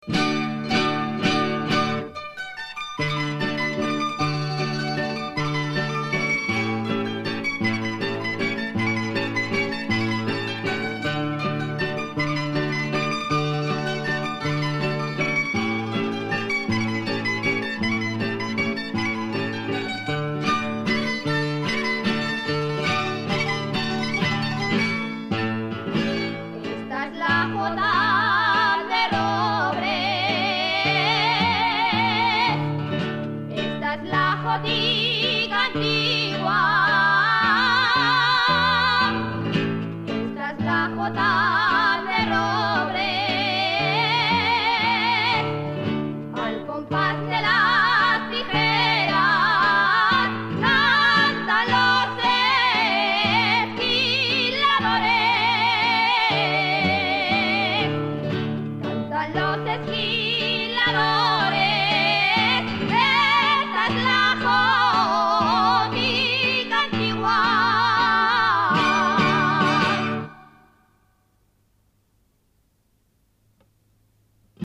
jota_de_robres.mp3